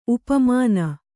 ♪ upmāna